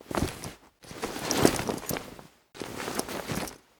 looting_1.ogg